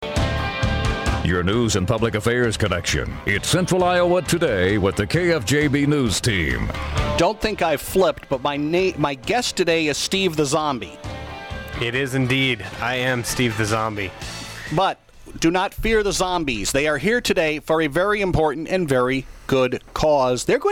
Audio KFJB Interview